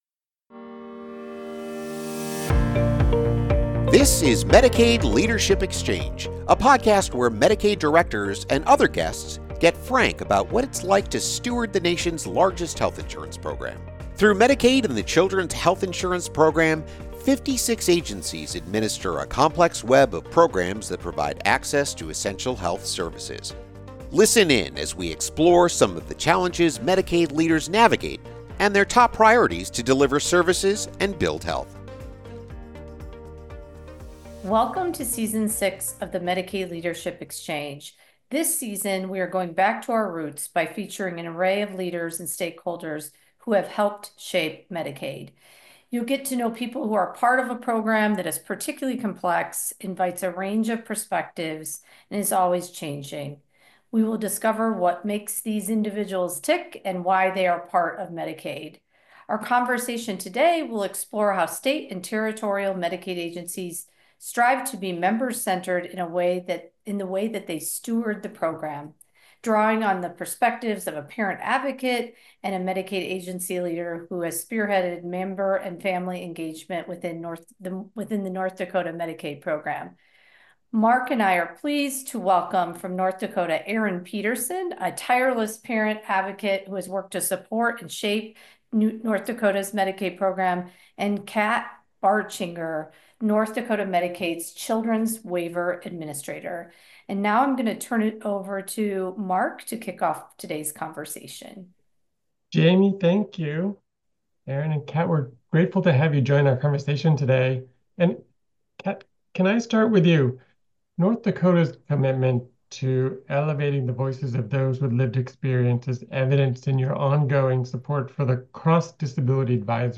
The conversation features perspectives from a North Dakota Medicaid leader and parent advocate: